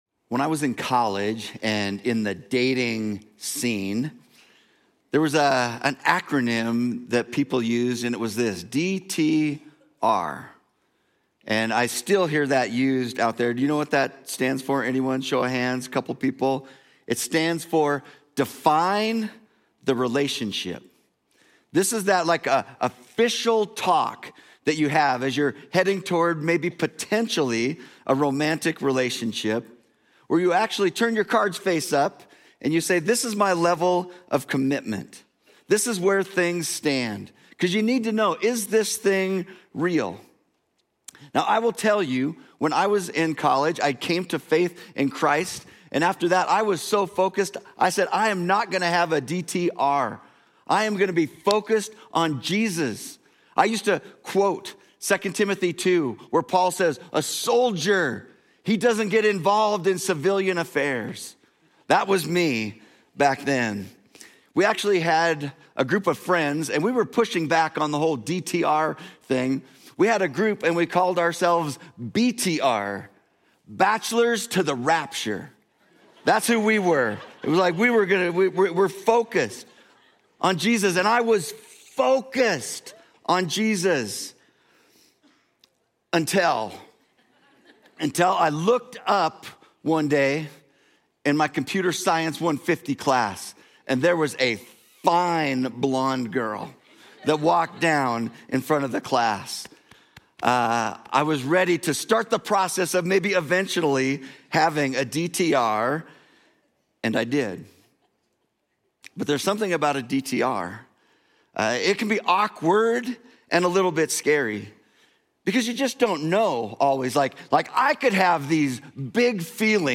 Lead Pastor Referenced Scripture